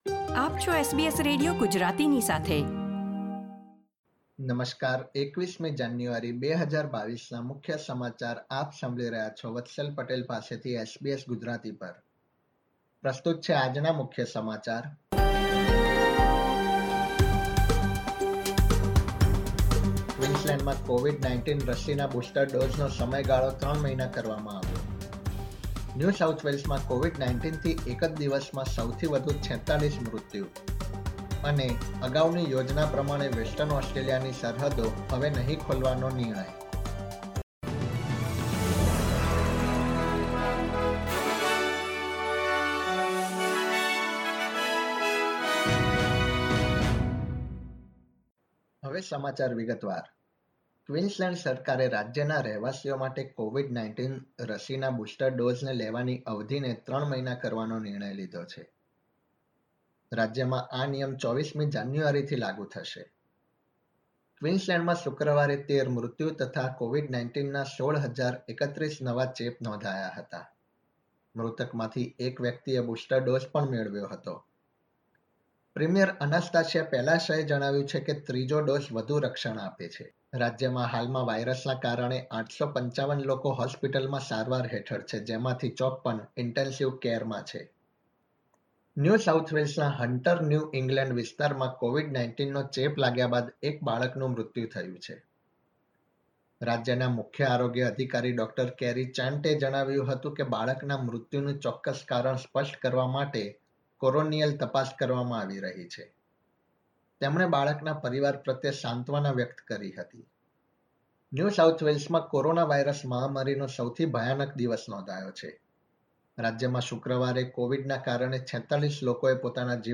SBS Gujarati News Bulletin 21 January 2022